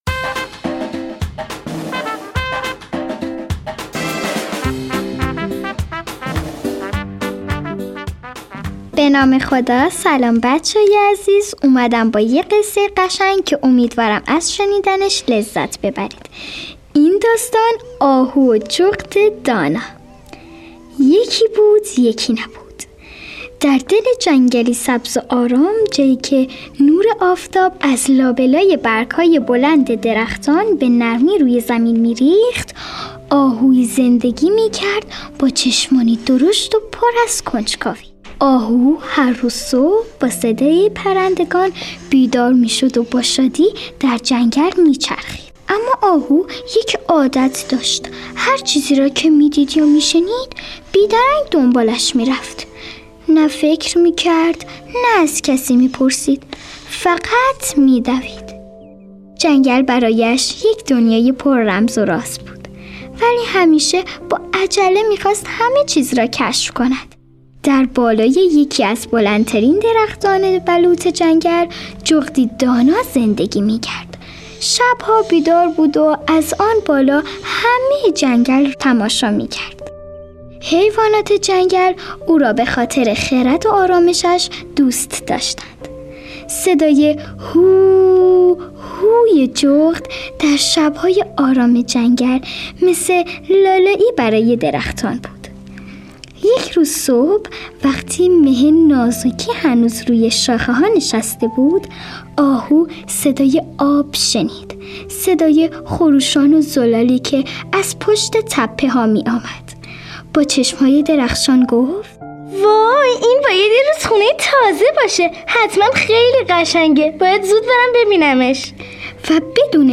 قصه های کودکانه صوتی – این داستان: آهو و جغد دانا
تهیه شده در استودیو نت به نت